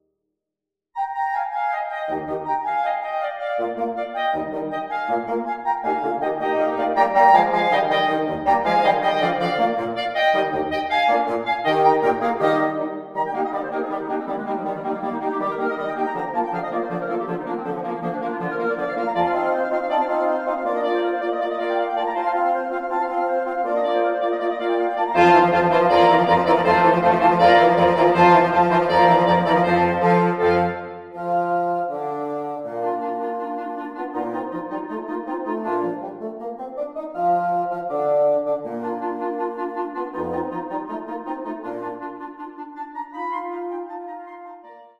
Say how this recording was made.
(Audio generated by Sibelius/NotePerformer)